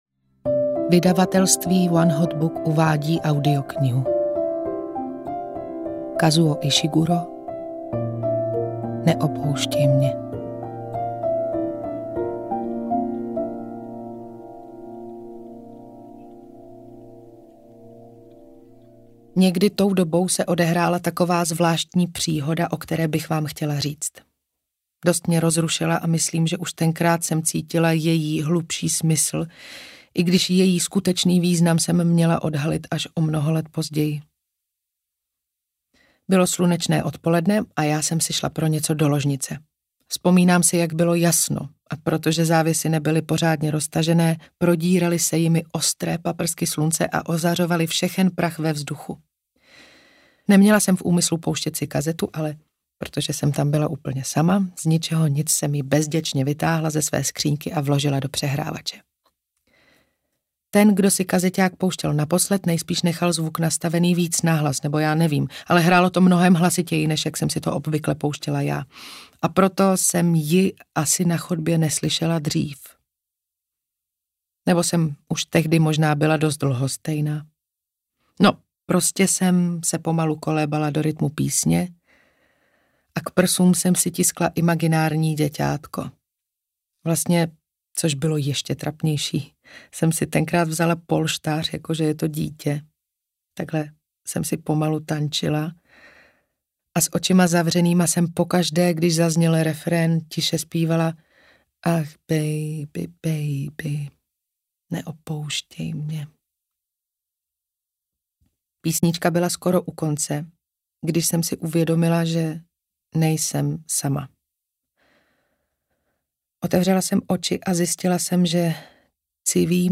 Neopouštej mě audiokniha
Ukázka z knihy